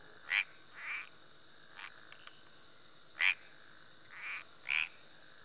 frog1.wav